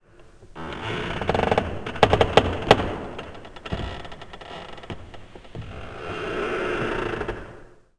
woodcreak2a.wav